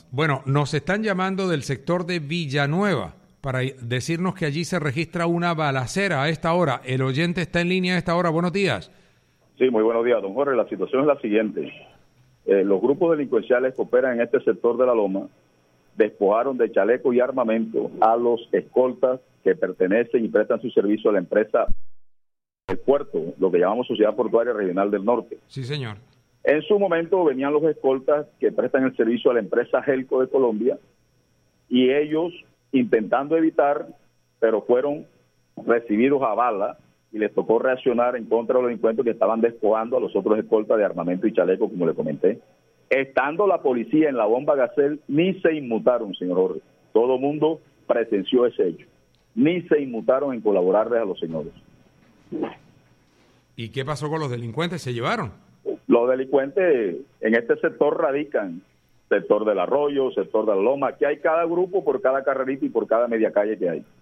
Un oyente de Atlántico en Noticias denunció que policías que se encontraban muy cerca de los hechos no reaccionaron de manera oportuna.